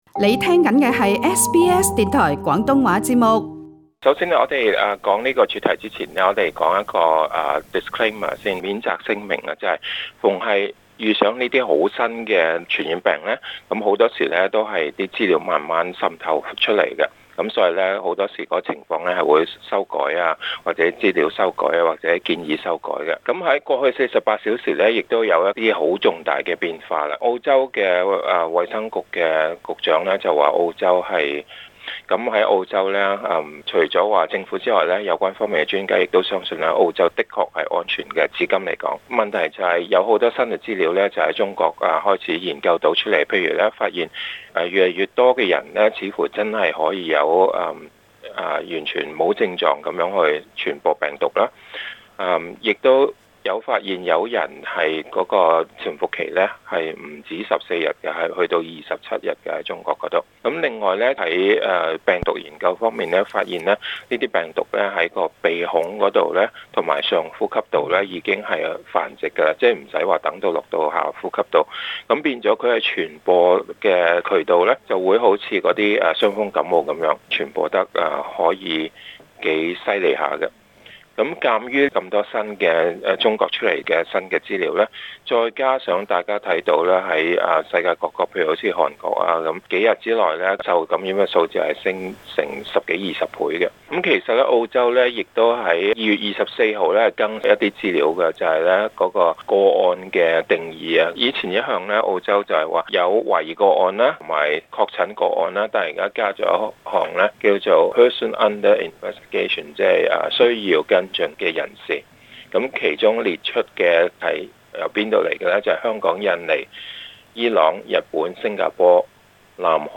更多訪問的詳情，請收聽本台的足本錄音。